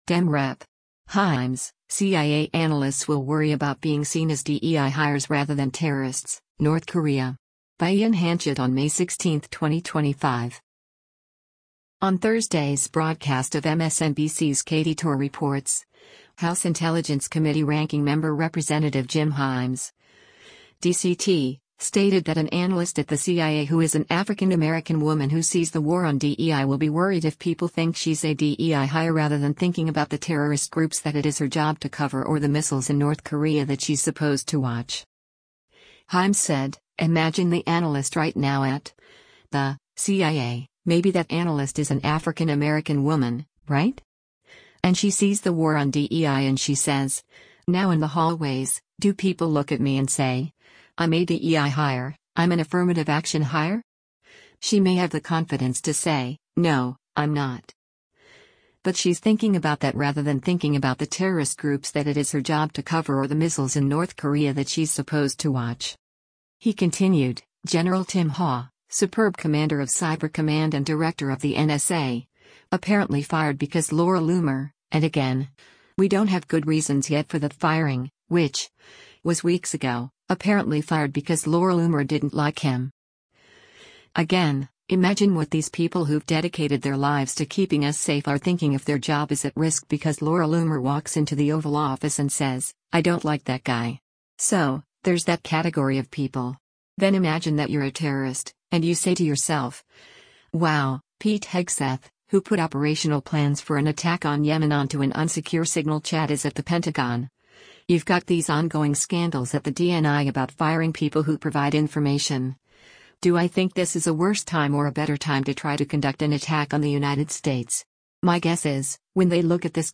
Video Source: MSNBC
On Thursday’s broadcast of MSNBC’s “Katy Tur Reports,” House Intelligence Committee Ranking Member Rep. Jim Himes (D-CT) stated that an analyst at the CIA who is an African American woman who “sees the war on DEI” will be worried if people think she’s a DEI hire “rather than thinking about the terrorist groups that it is her job to cover or the missiles in North Korea that she’s supposed to watch.”